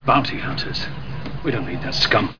An Imperial officer's comment about Bounty Hunters